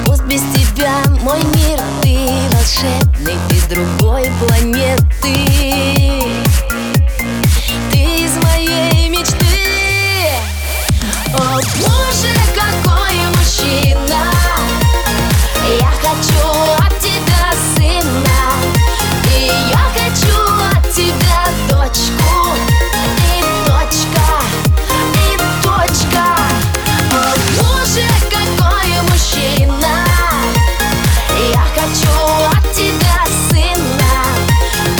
Жанр: Русская поп-музыка / Поп / Русский рок / Русские
# Pop